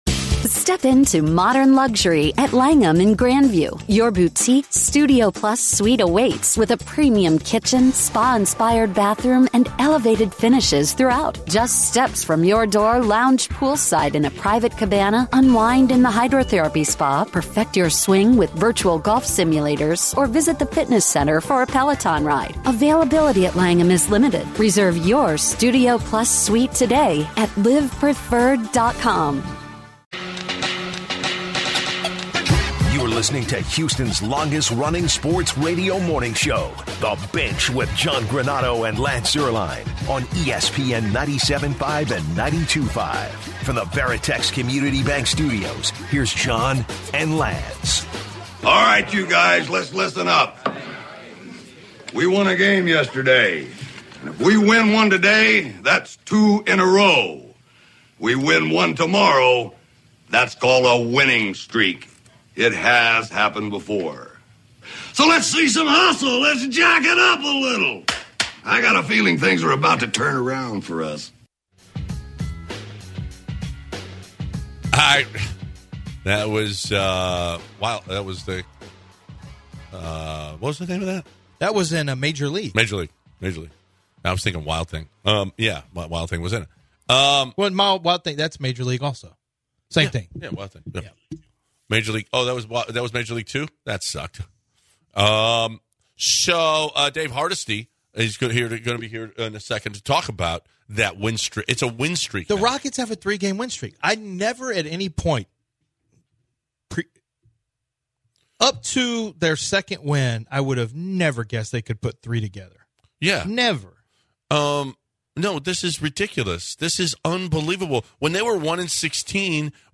They wrap up the second hour discussing the occasional invitational going on Wednesday Dec 1 and take some calls.